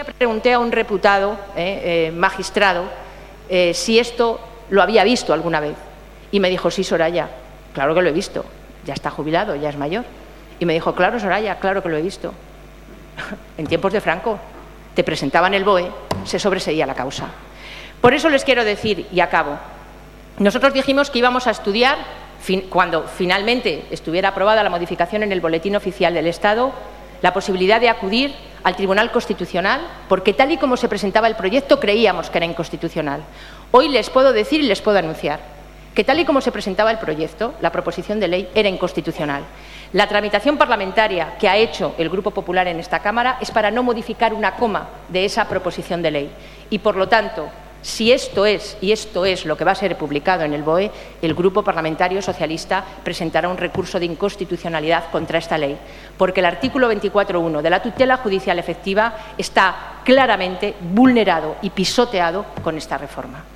Soraya Rodríguez inaugura la jornada parlamentaria organizada por la Asociación Pro Derechos Humanos en el Congreso 4/03/2014